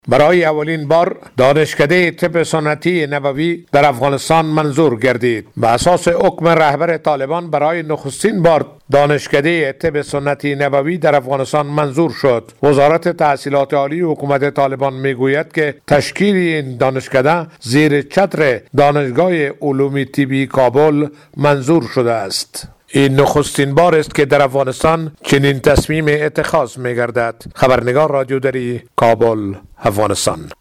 گزارش فرهنگی